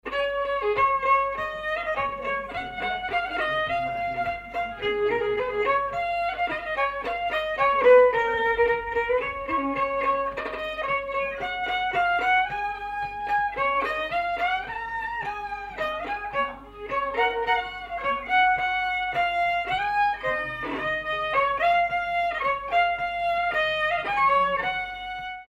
Danse
circonstance : bal, dancerie
Pièce musicale inédite